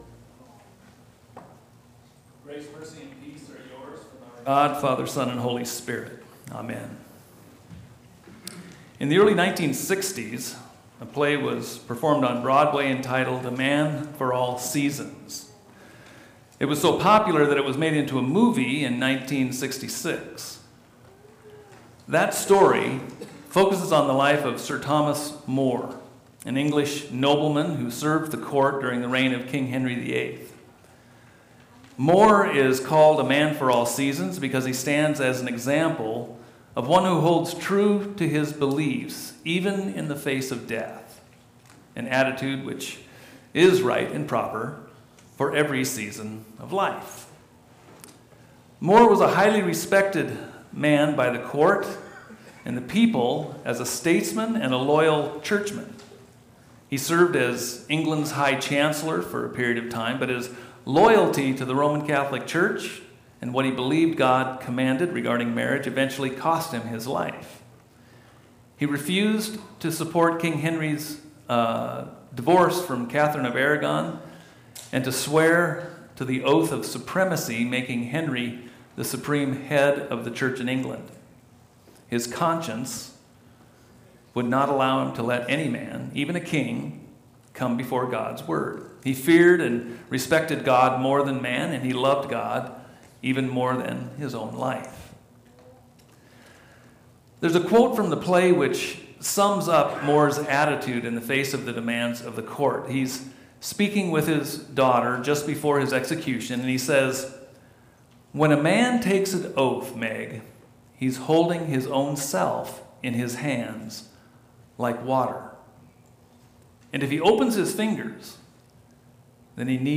Sunday Service Sermons